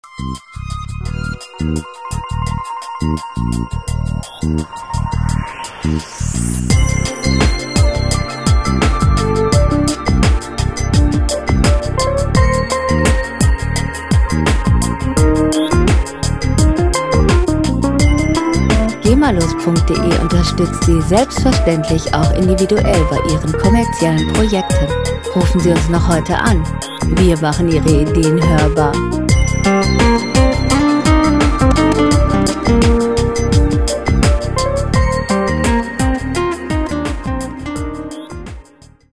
Lounge Musik - Cool und lässig
Musikstil: Nu Jazz
Tempo: 85 bpm
Tonart: C-Moll
Charakter: sorglos, ungetrübt